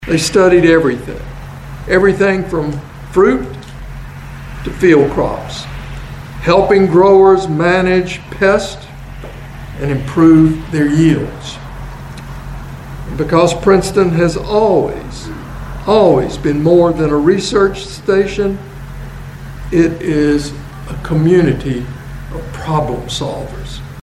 The University of Kentucky leadership and board of trustees were joined by farmers, state and local officials, and community members to celebrate a century of service at the Research and Education Center in Princeton.